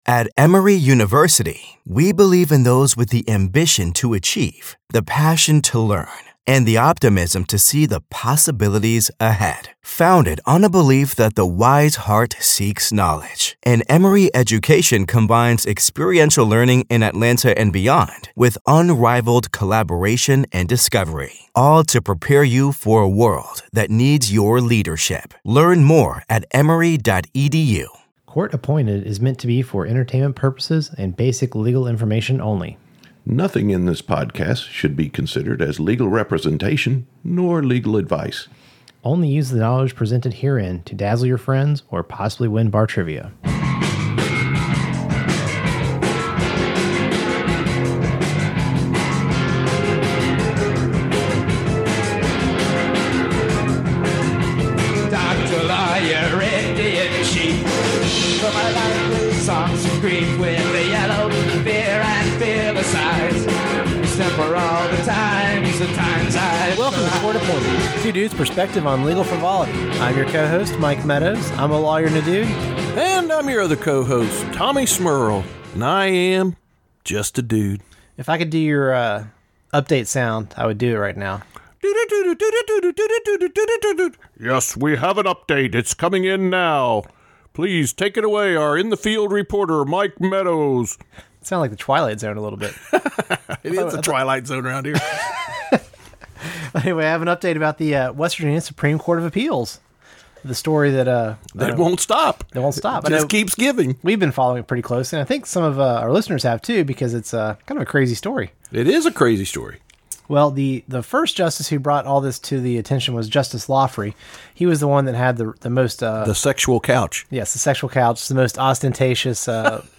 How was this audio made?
P.S. The sound quality is restored to normal so relax.